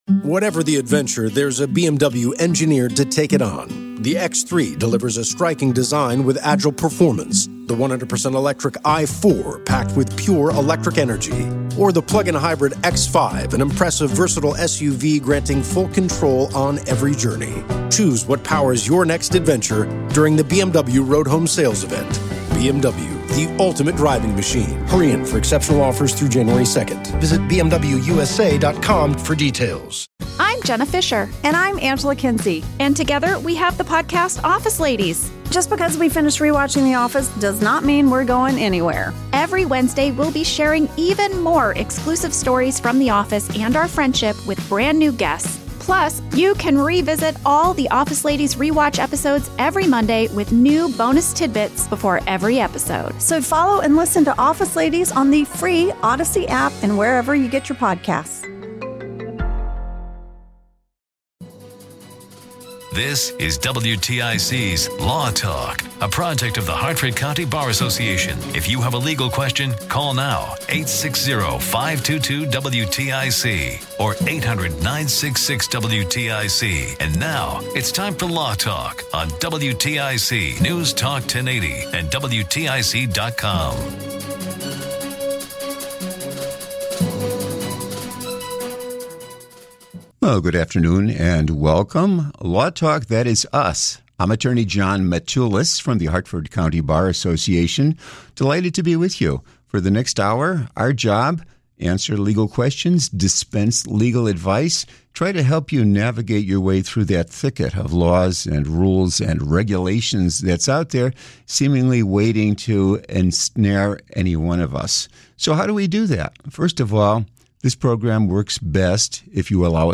Calls: a listener in Meriden had a call about unusual events which occurred, even after his son's auto accident case was fully settled by the Insurance Company. a listener in Hartford wanted to know more about the "Mailbox Rule," and how someone could prove they actually mailed the document in question.